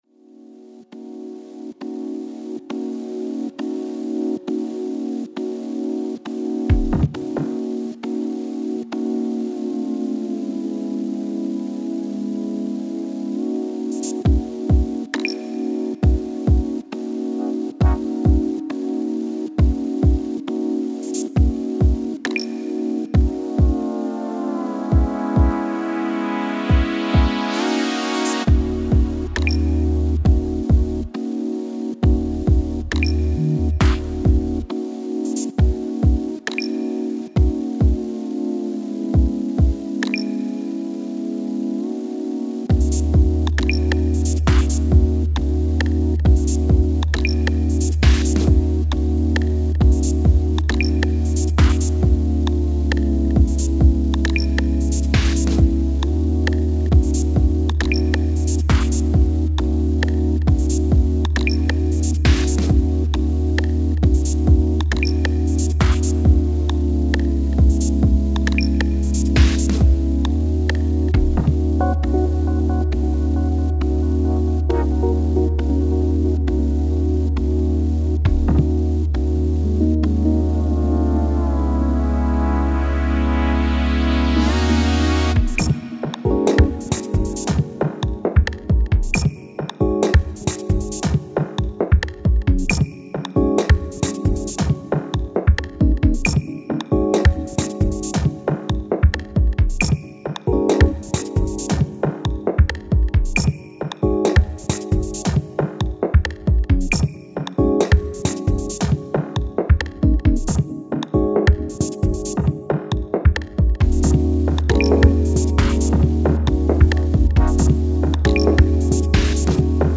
Genre Minimalism